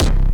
Kicks
Dre Kick3.wav